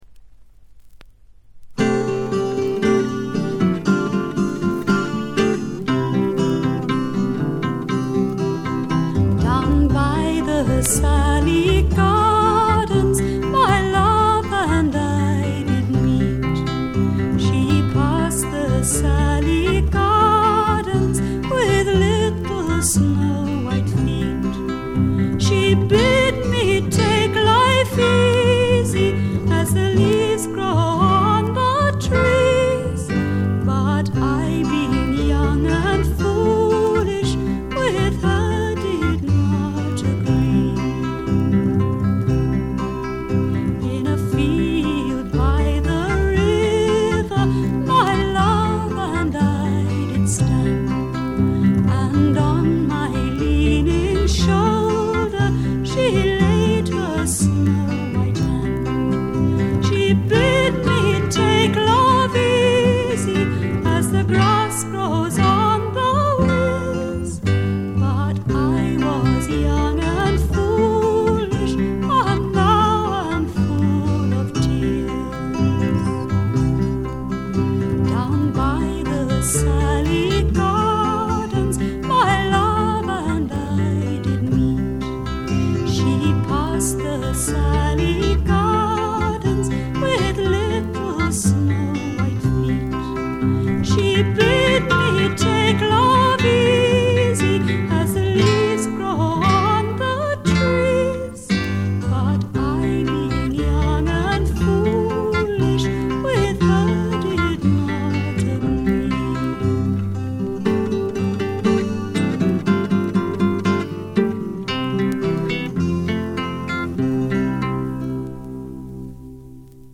全体に軽微なバックグラウンドノイズ。
英国フィメール・フォークの大名作でもあります。
内容はというとほとんどがトラディショナル・ソングで、シンプルなアレンジに乗せた初々しい少女の息遣いがたまらない逸品です。
モノラル盤です。
試聴曲は現品からの取り込み音源です。